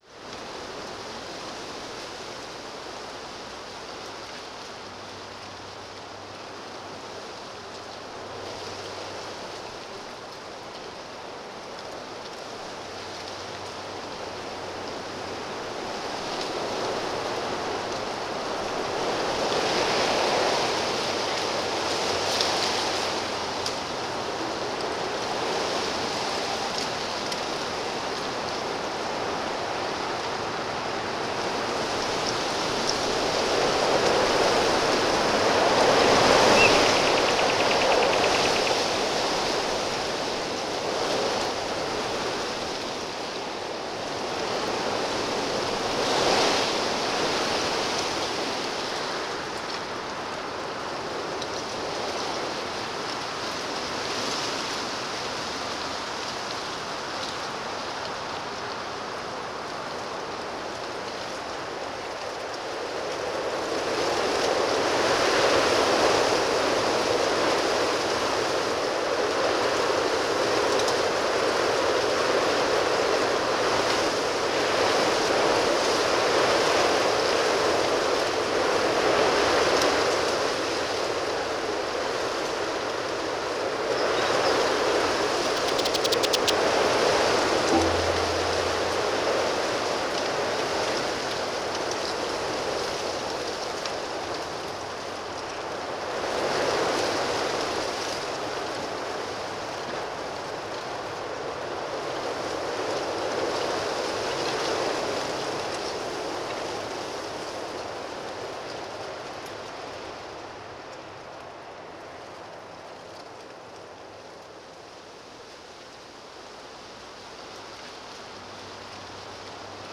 ambiance_withwind.wav